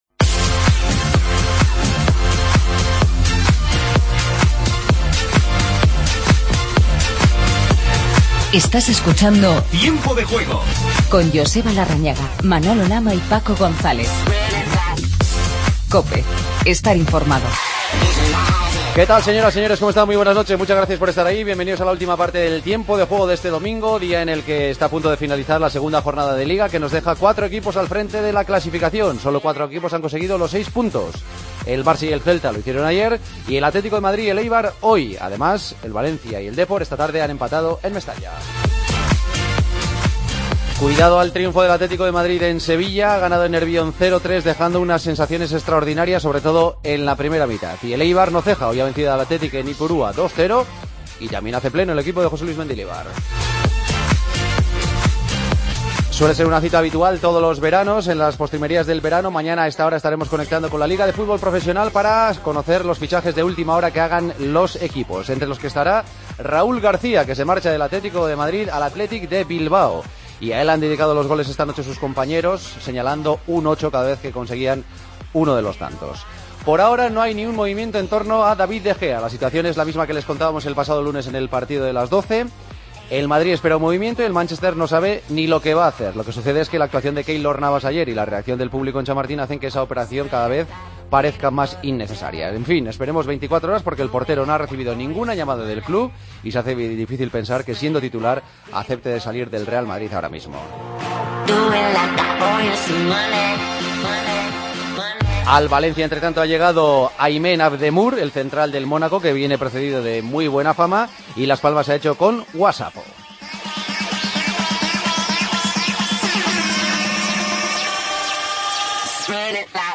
Vivimos los finales de los partidos Getafe-Granada y Las Palmas-Levante. El Atleti gana en Sevilla 0-3 y Raúl García se marcha al Athletic. Entrevista a Gabi.